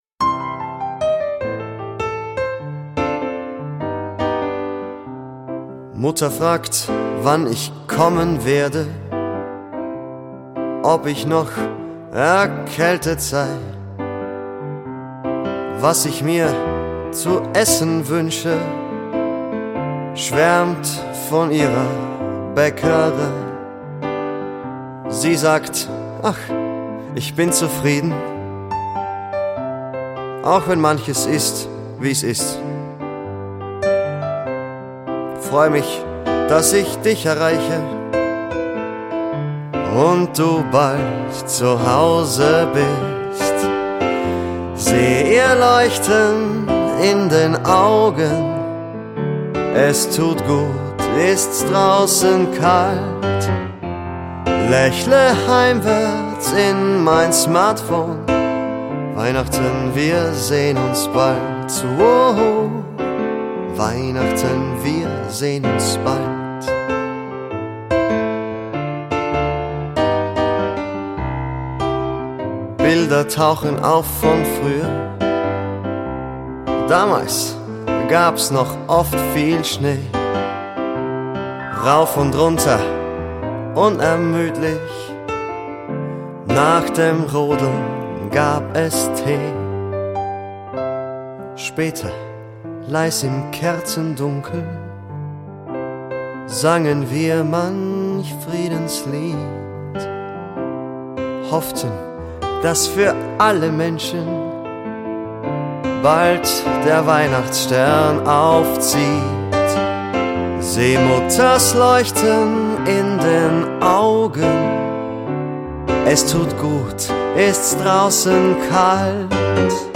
voice and piano
Dezember-Chanson